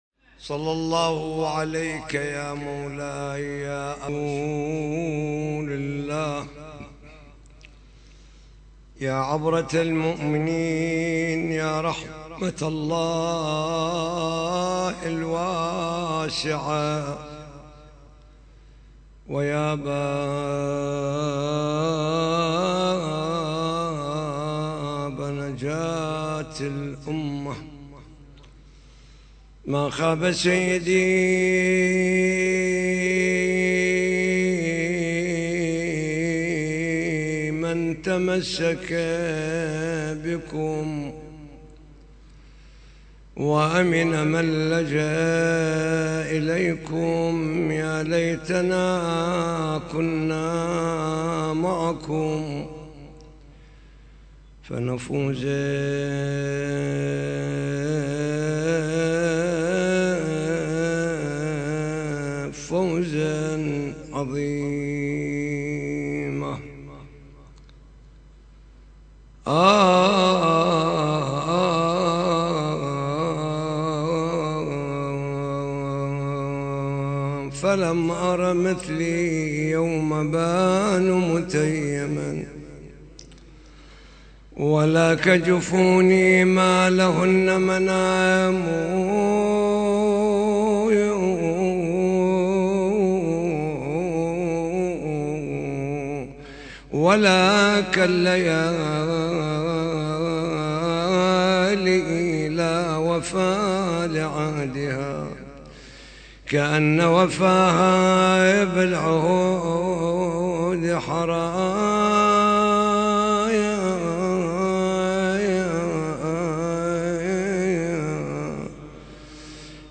محاضرة ليلة 20 جمادى الأولى